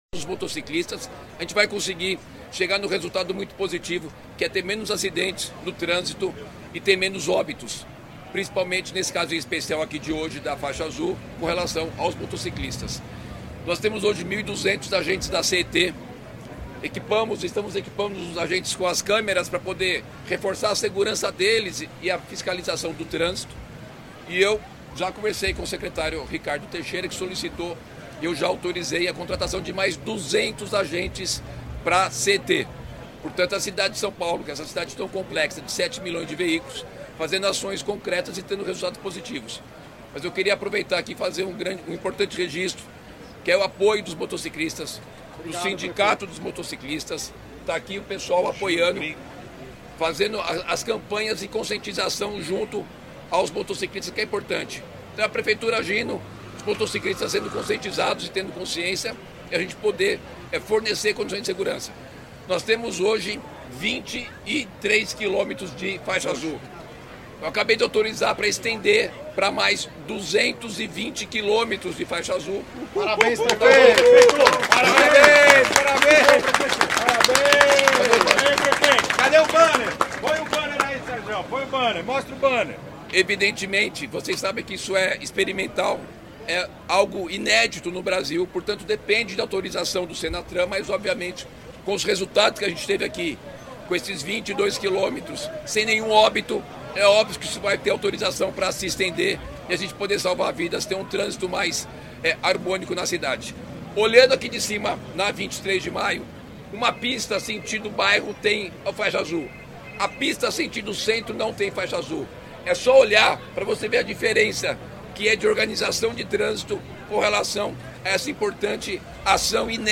jornalista especializado em transportes